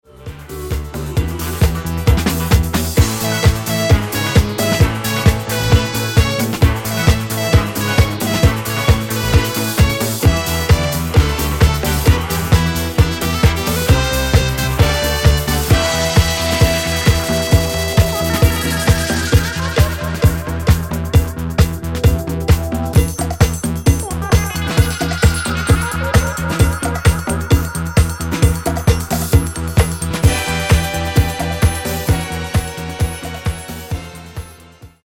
Genere:   Disco| Funky | Soul |